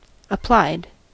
applied: Wikimedia Commons US English Pronunciations
En-us-applied.WAV